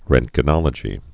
(rĕntgə-nŏlə-jē, -jə-, rŭnt-)